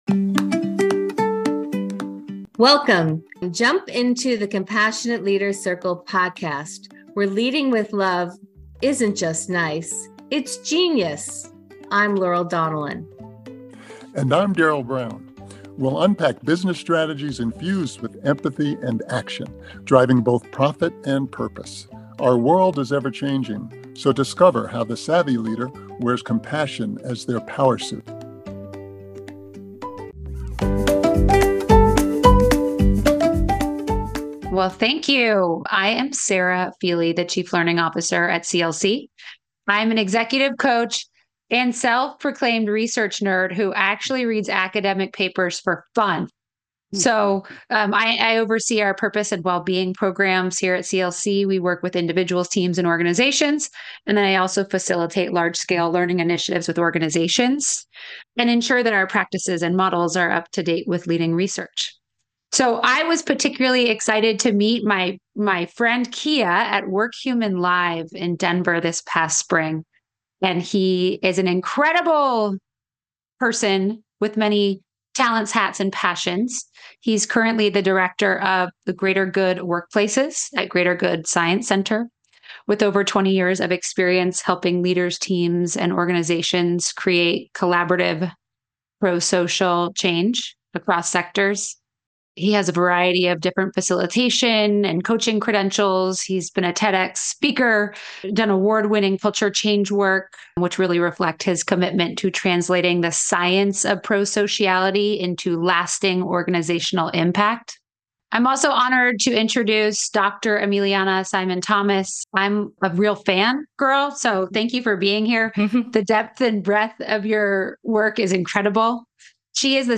I had the great honor of interviewing one of these researchers and her colleague last week.